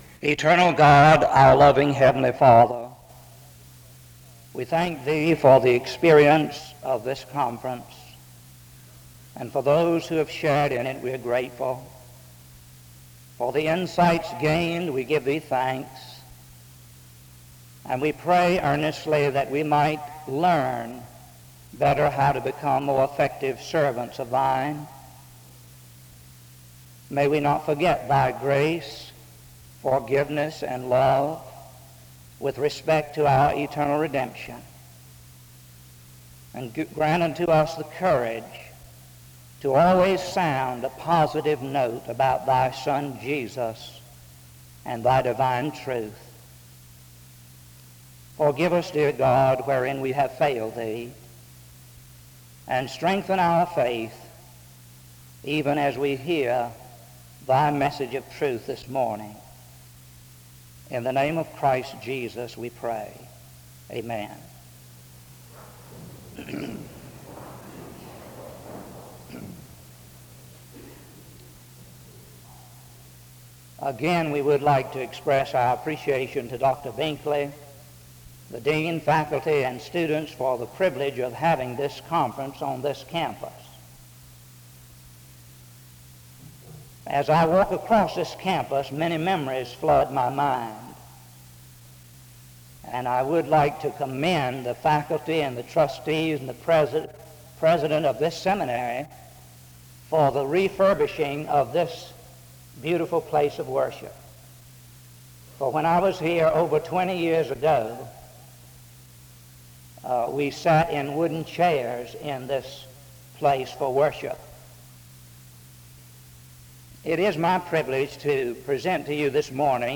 The service begins with an opening word of prayer from 0:00-1:04. An introduction to the speaker is given from 1:14-2:22.
SEBTS Chapel and Special Event Recordings SEBTS Chapel and Special Event Recordings